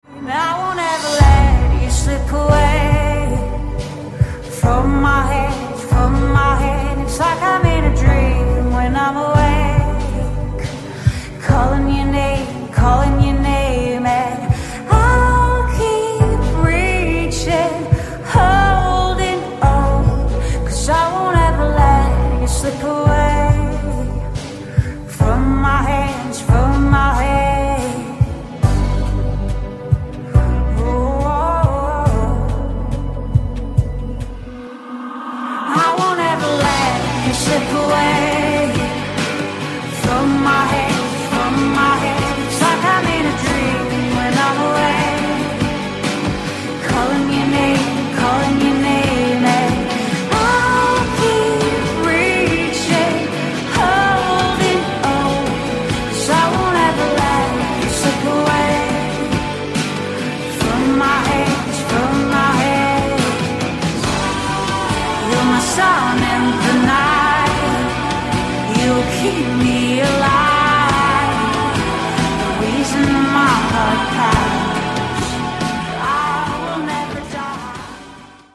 • Качество: 256, Stereo
женский вокал